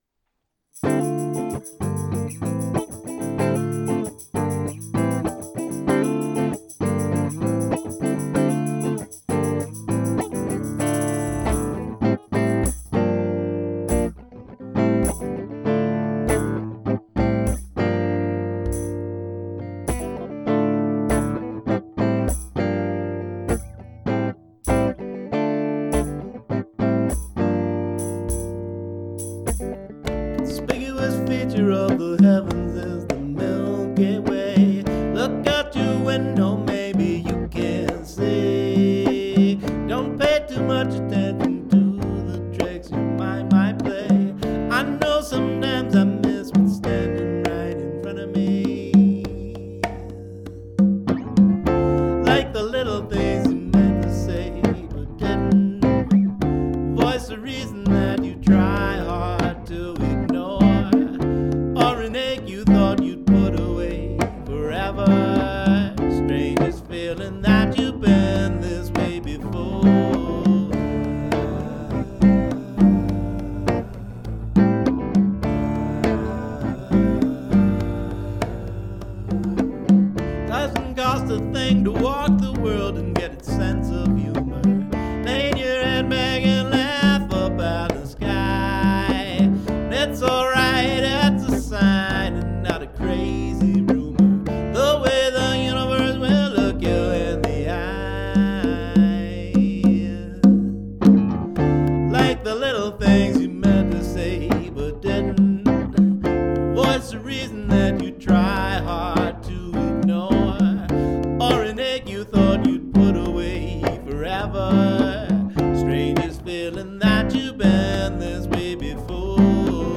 congas, percussion
Acoustic Soul with a Latin Groove